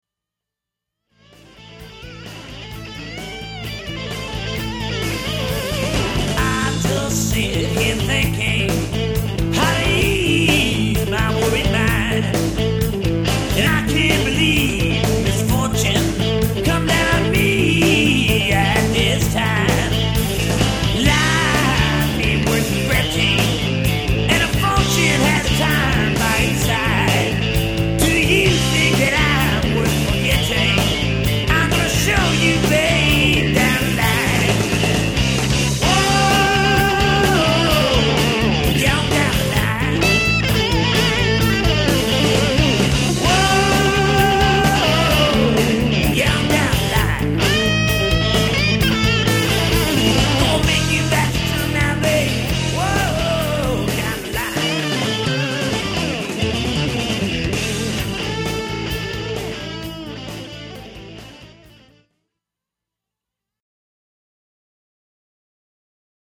searing extended solo trade-offs